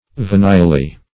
[1913 Webster] -- Ve"ni*al*ly, adv.